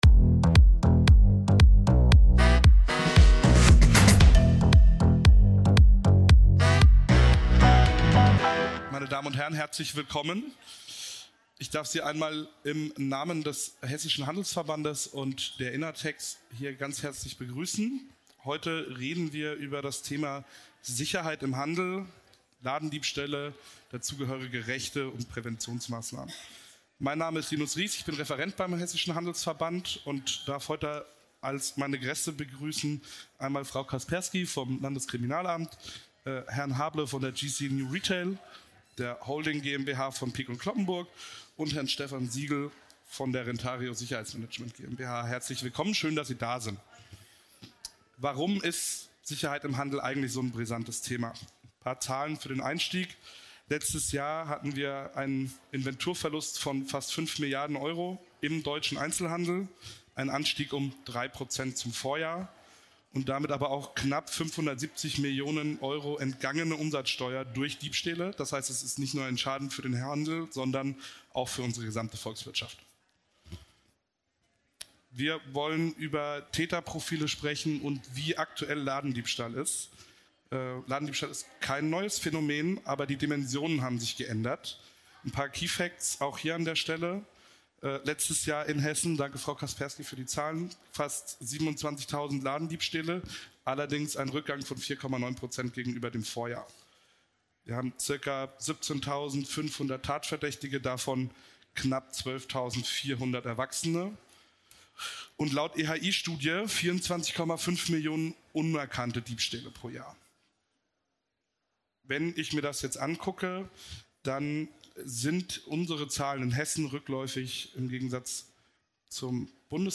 Handelsveband Expert Session (Deutsch) Ladendiebstahl betrifft viele – vom Innenstadtladen bis zum Concept Store im ländlichen Raum.
In diesem offenen Q&A-Format beantworten drei Sicherheitsexpert:innen Fragen, geben Tipps und Orientierung für einen sicheren, souveränen Umgang mit kritischen Situationen.